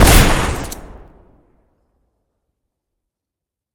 shoot2.ogg